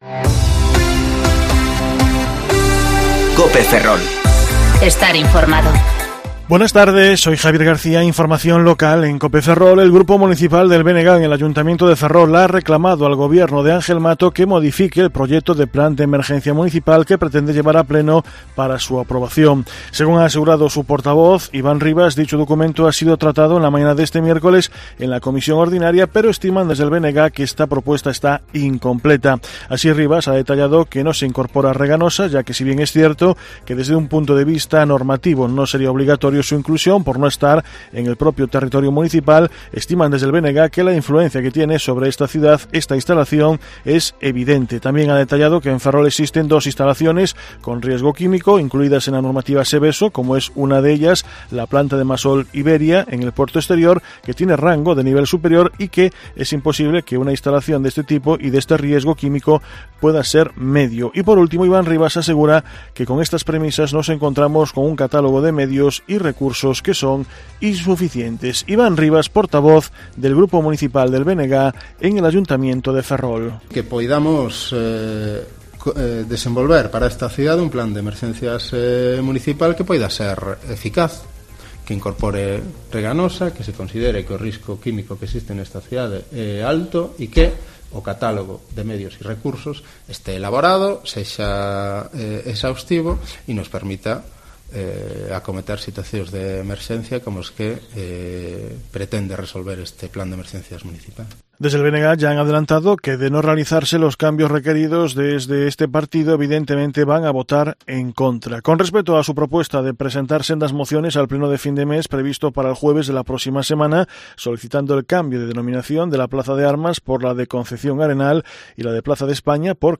Informativo Mediodia COPE Ferrol 15/1/2020 (De 14,20 a 14,30 horas)